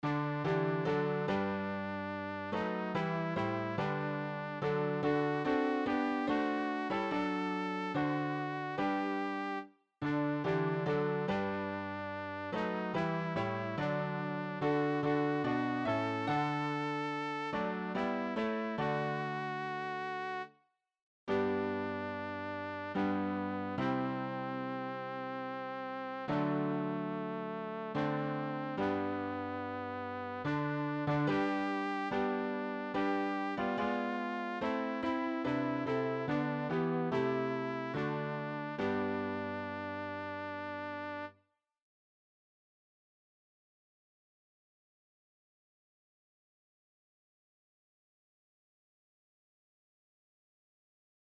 Übehilfen für das Erlernen von Liedern
alt-vonmoos-dorma-bain.mp3